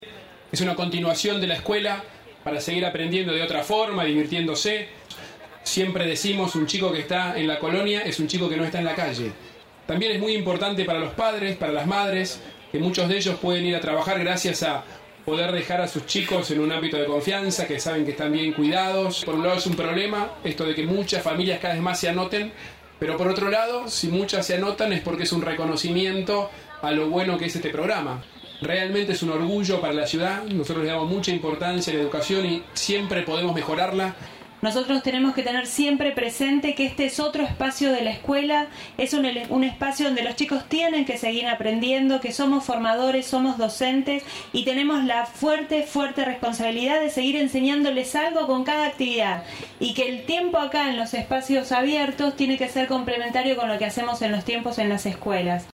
El jefe de Gobierno porteño, Horacio Rodríguez Larreta, presentó este viernes en el Parque de la Ciudad el programa Vacaciones en la Escuela, al cual definió como “una continuación de la escuela para seguir aprendiendo de otra forma, divirtiéndose”.